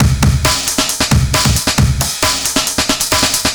cw_amen10_135.wav